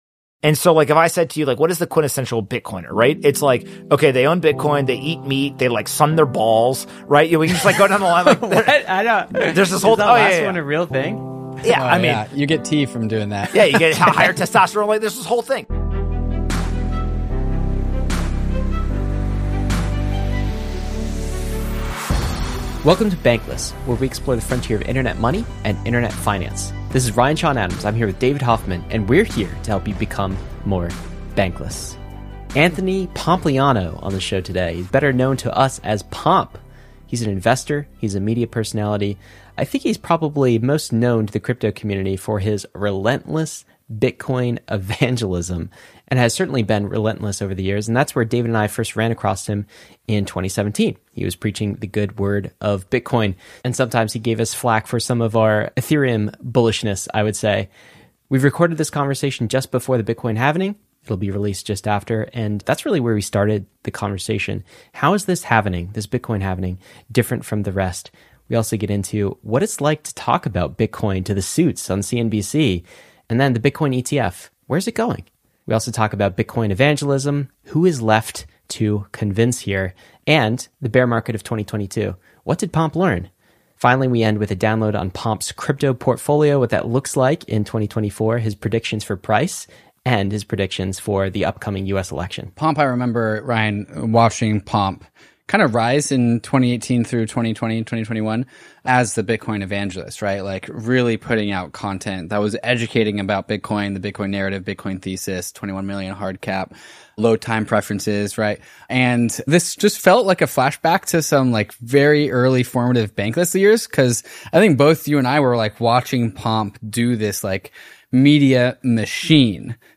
Bankless chevron_right Anthony Pompliano: Bitcoin, Portfolio for 2024, Institutional Adoption & Crypto Tribalism Apr 22, 2024 auto_awesome In this engaging conversation, Anthony Pompliano, an influential investor and Bitcoin advocate, dives deep into the upcoming Bitcoin halving and what it means for the future. He discusses the rise of institutional adoption, highlighting key trends shaping how finance views Bitcoin. The dialogue also touches on crypto tribalism and the evolving landscape of Bitcoin ETFs.